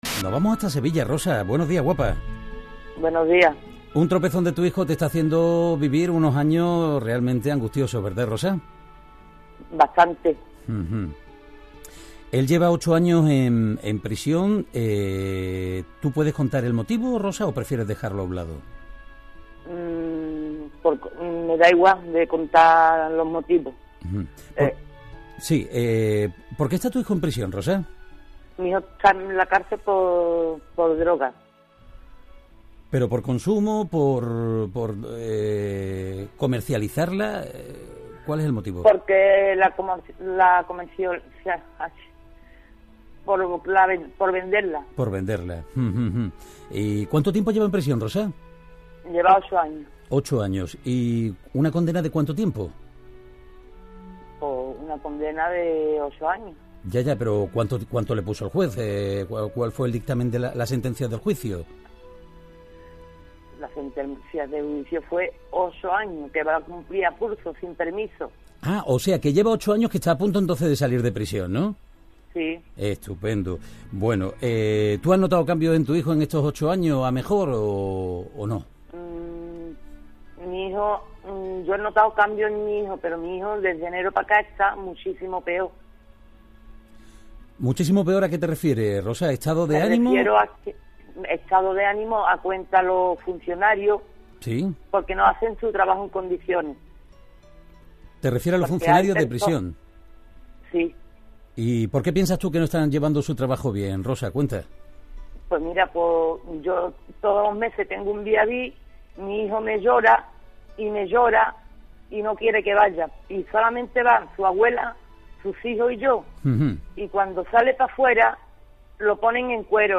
ha llamado a la radio con ganas y necesidad de desahogarse.